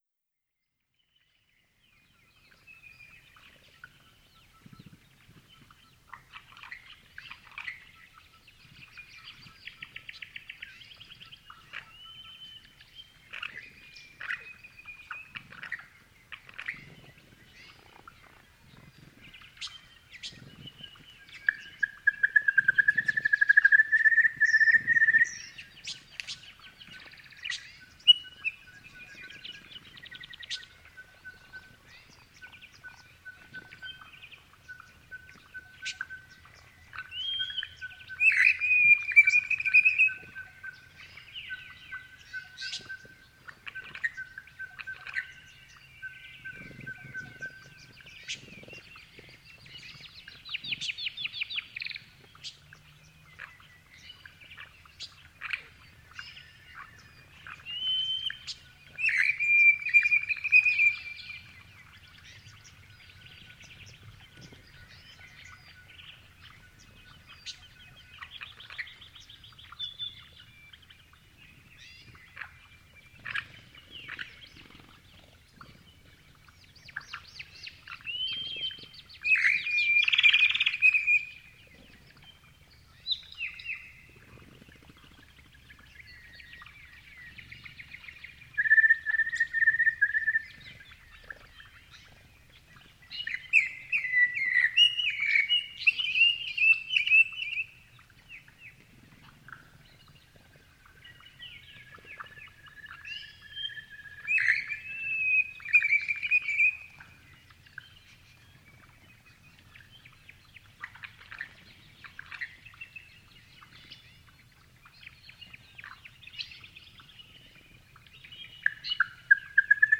• honeyeater birds - crested bellbirds.wav
Honeyeater_Birds_-_Crested_Bellbirds_Sounds_hej.wav